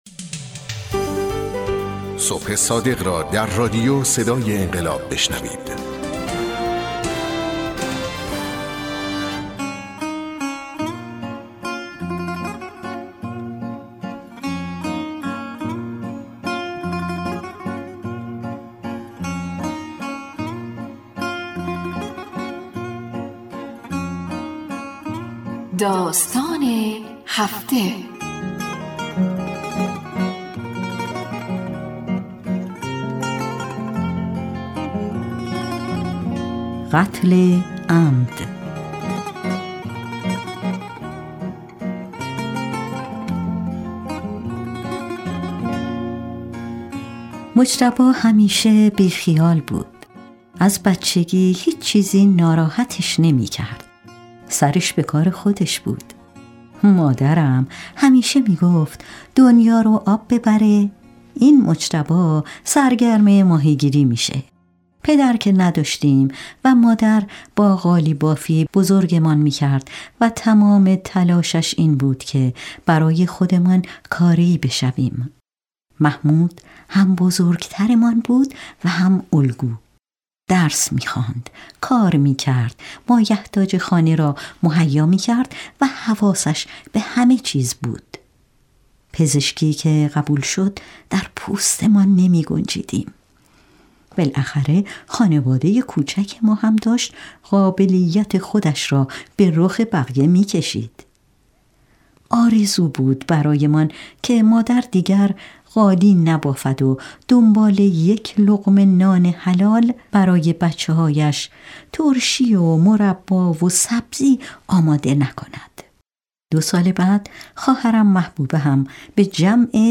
رادیو صدای انقلاب 1754 | داستان - قتل عمد